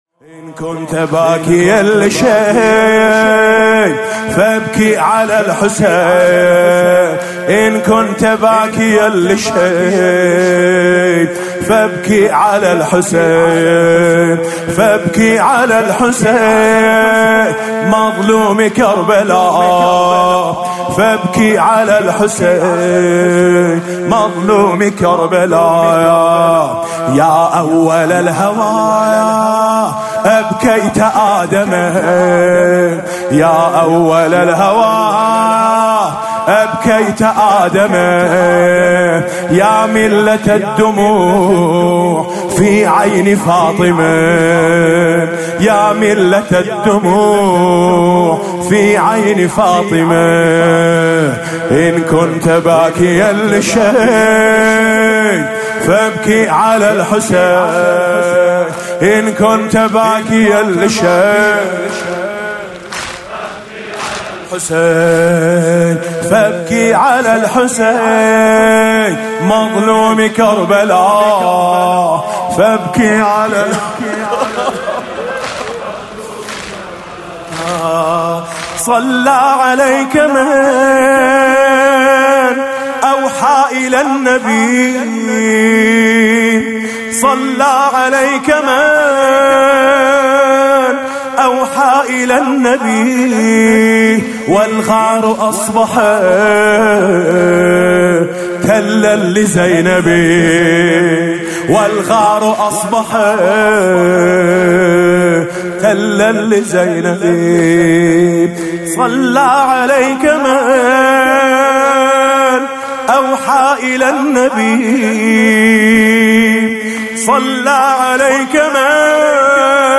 مداحی شب پنجم محرم ۹۹
بخش ۱ : گلوی سرخ عبدالله آهنگ حسن دارد – روضه
بخش ۶ : اگه یه کبوتر بودم – شور